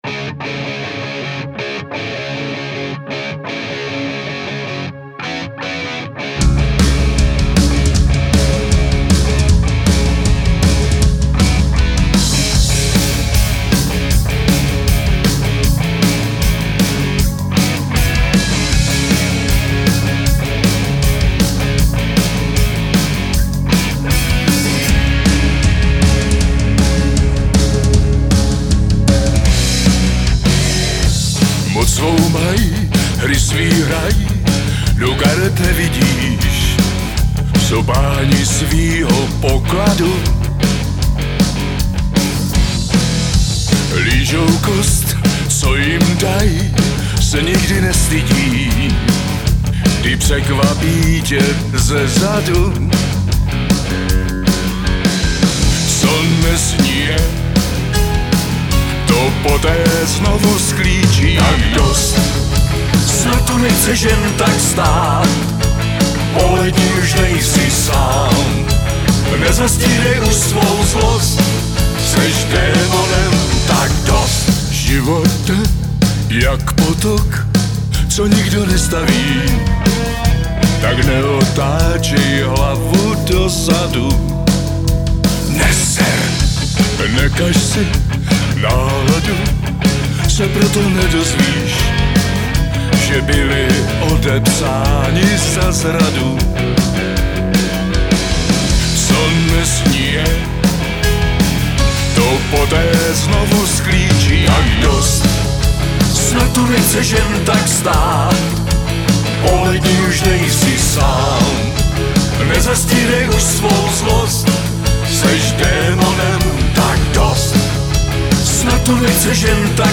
zpěv, řev, sípot
bicí
kytara, zpěv
klávesy, zpěv